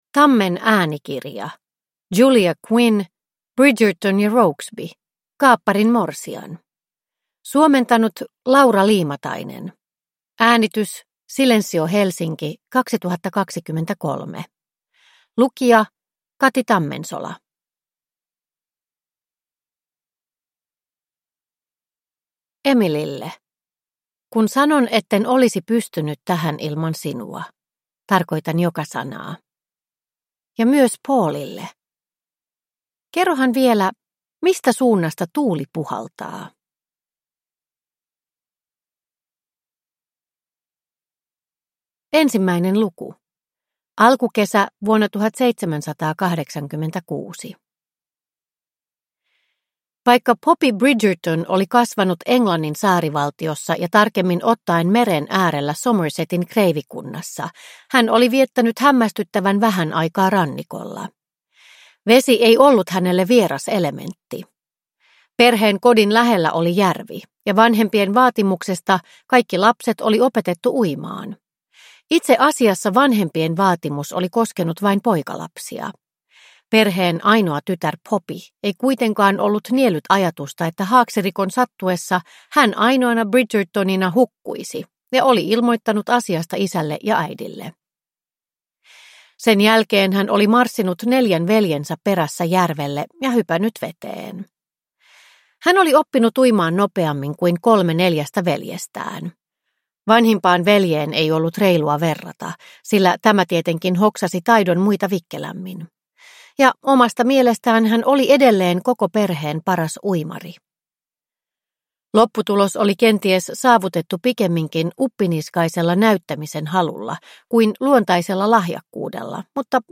Bridgerton & Rokesby: Kaapparin morsian – Ljudbok – Laddas ner